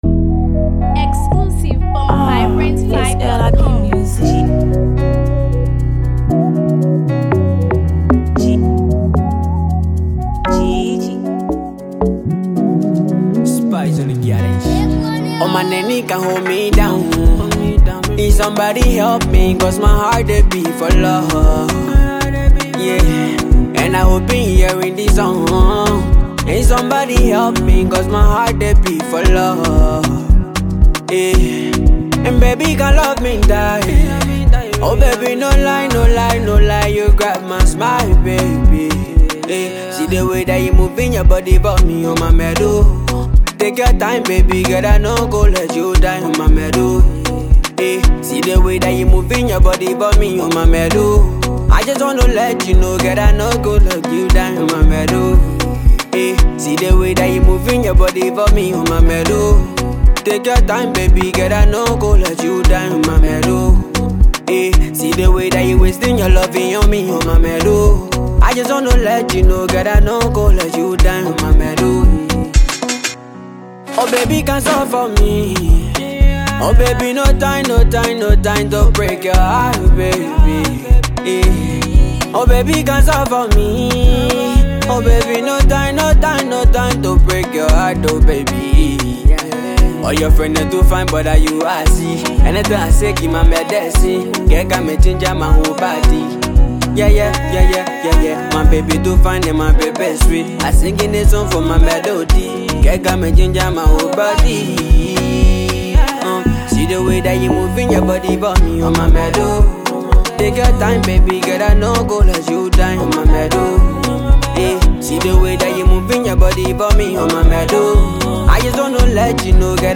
catchy tune